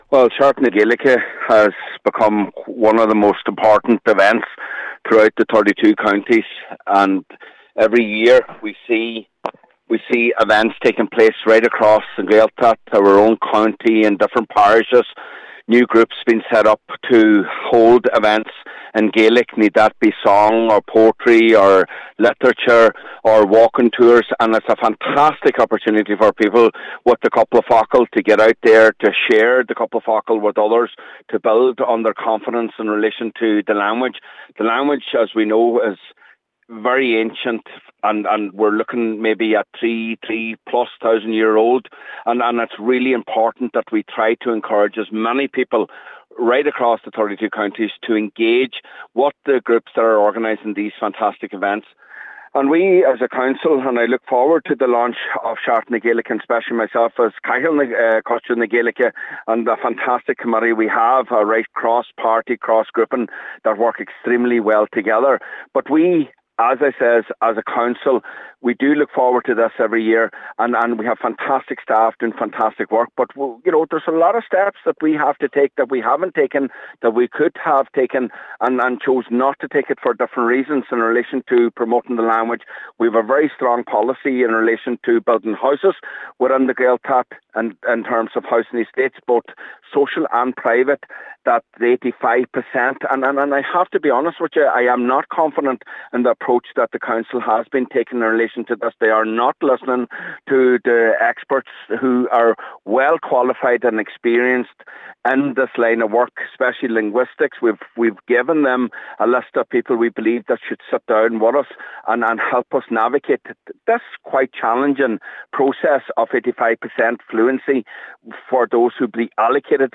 (Interview in Full)